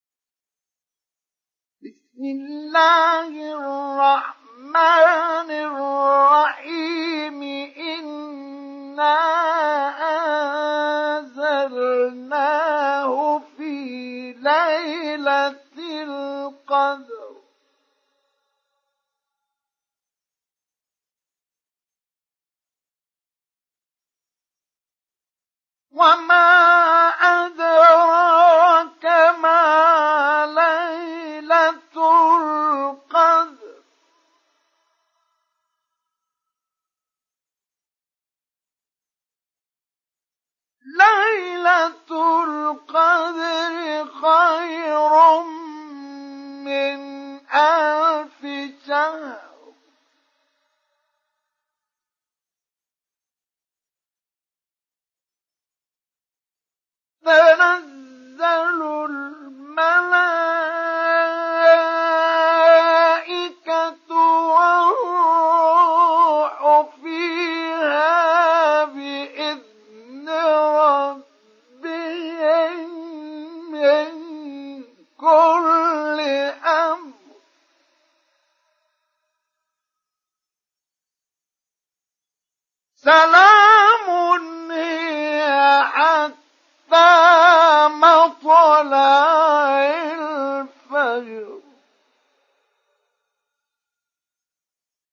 Surah Al Qadr Download mp3 Mustafa Ismail Mujawwad Riwayat Hafs from Asim, Download Quran and listen mp3 full direct links
Download Surah Al Qadr Mustafa Ismail Mujawwad